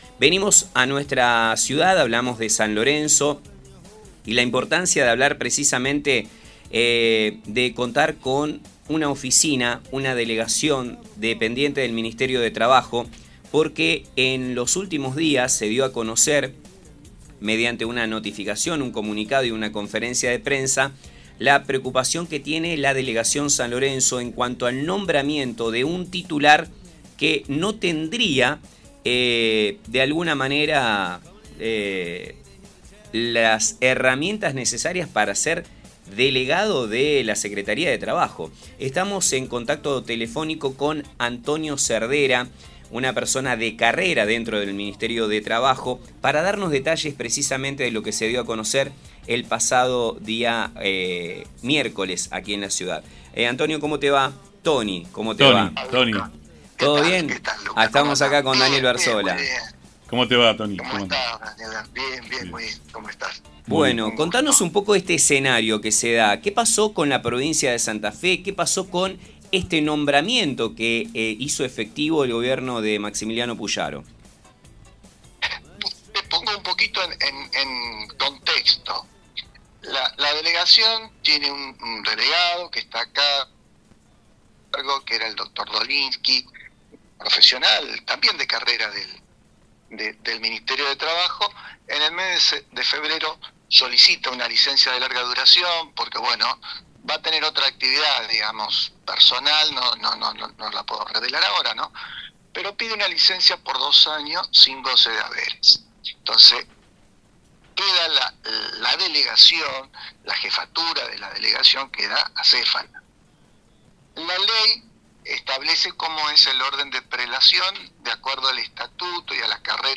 En una reciente entrevista en el programa «El Muelle»